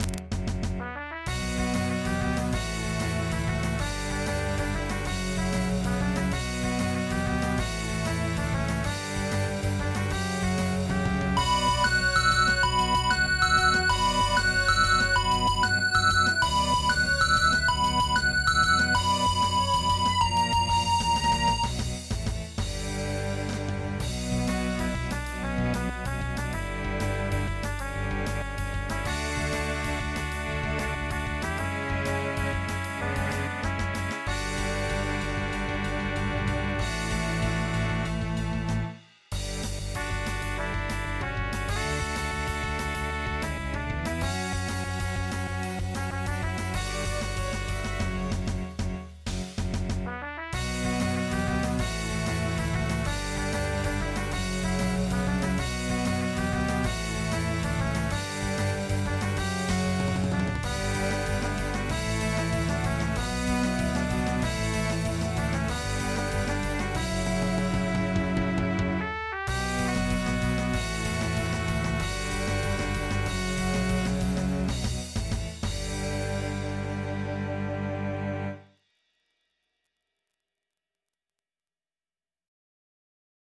MIDI 14.99 KB MP3